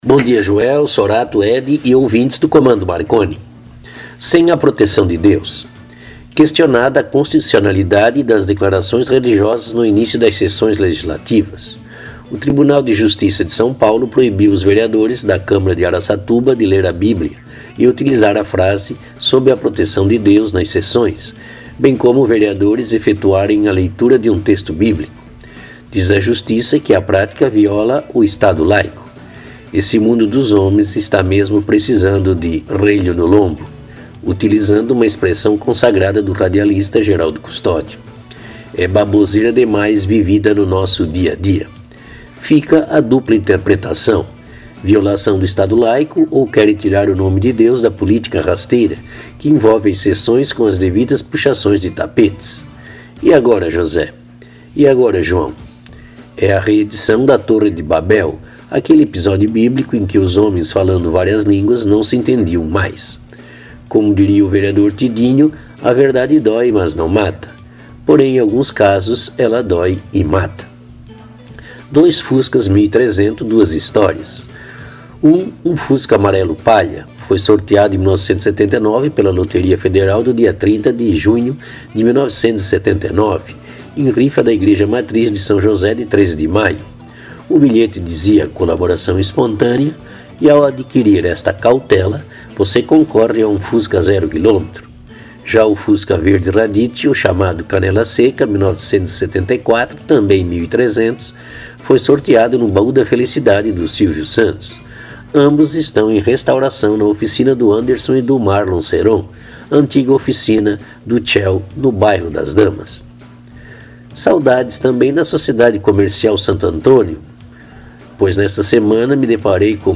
A atração é apresentada de modo espirituoso e com certas doses de humorismo e irreverência, além de leves pitadas de ironia quando necessárias.